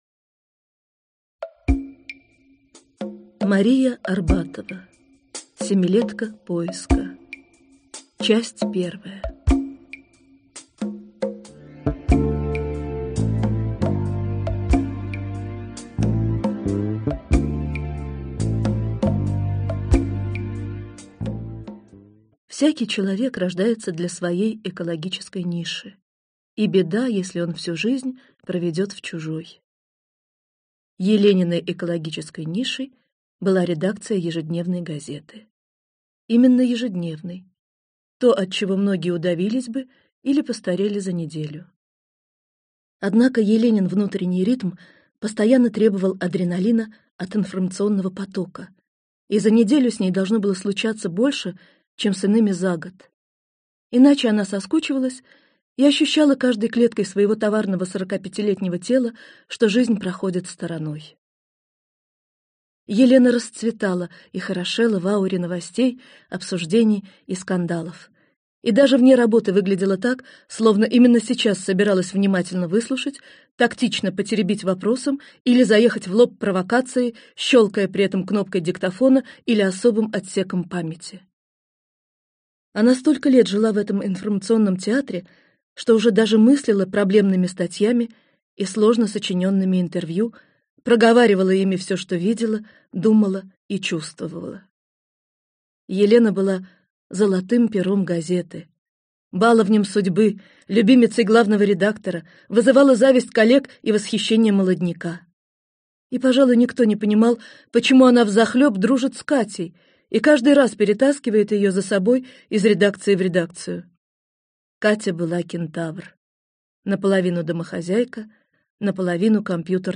Аудиокнига Семилетка поиска | Библиотека аудиокниг
Прослушать и бесплатно скачать фрагмент аудиокниги